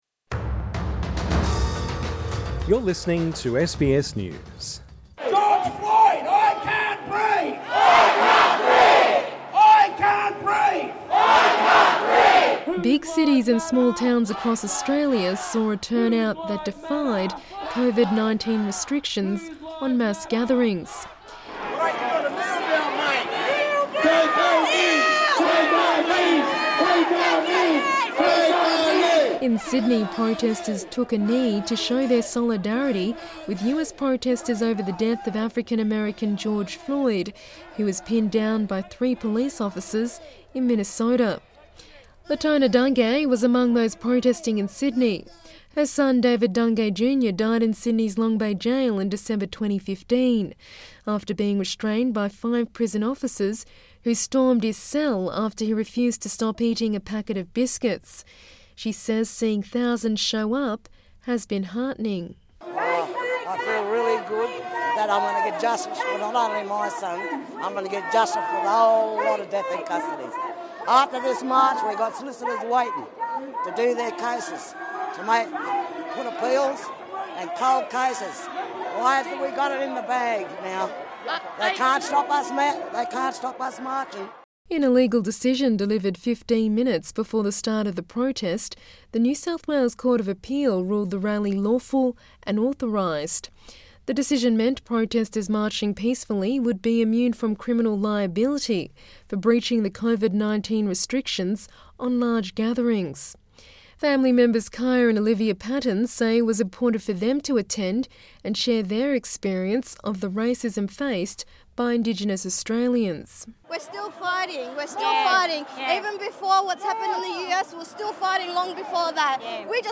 Tens of thousands of Australians have taken part in anti-racism rallies, chanting Black Lives Matter, in a growing wave of global anger over the death of George Floyd in the United States - and the issue of police brutality.